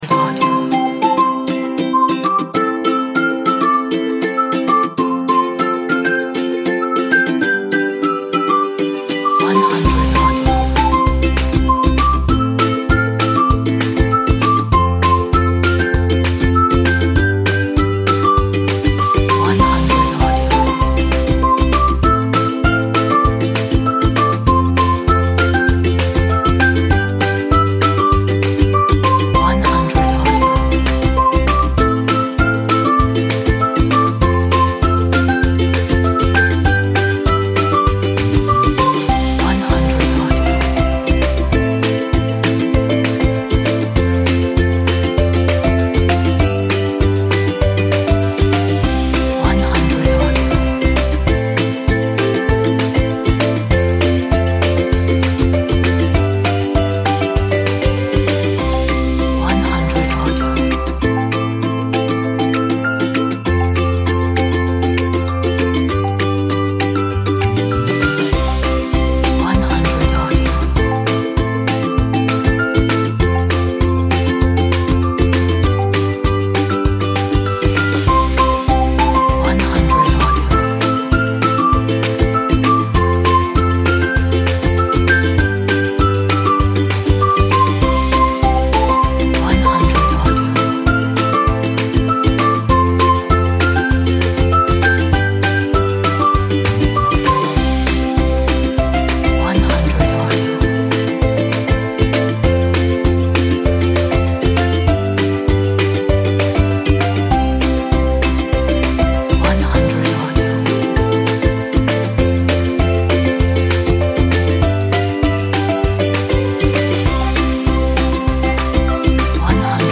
is positive and happy children's music